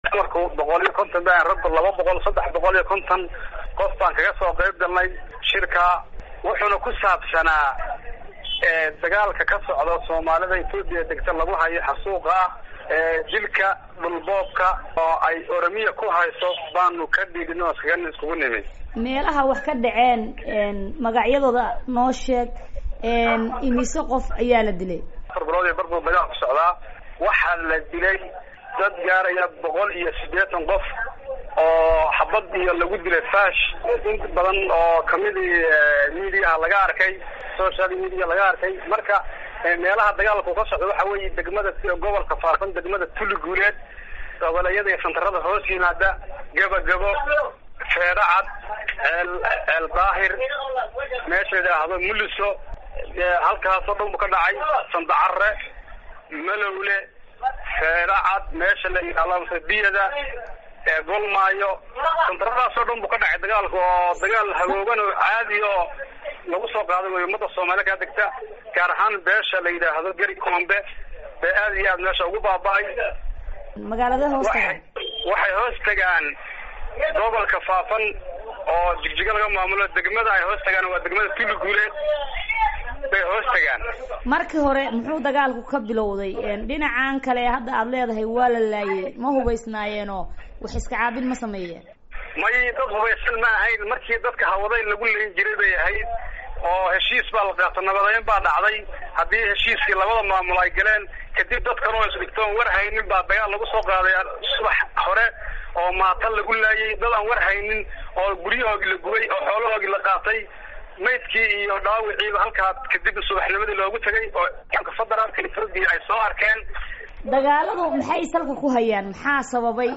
Boosaaso